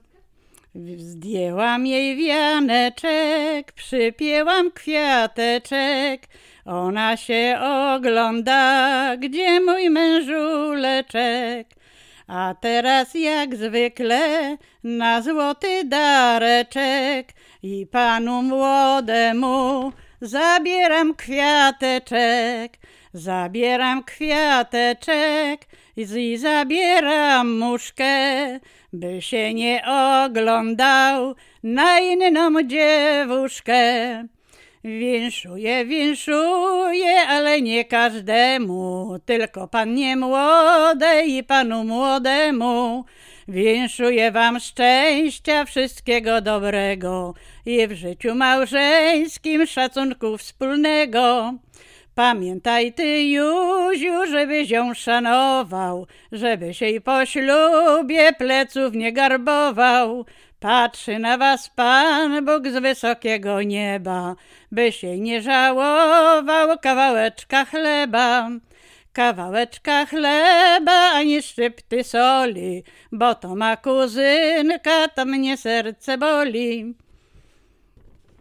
Łęczyckie
Przyśpiewki oczepinowe
wesele weselne oczepinowe przyśpiewki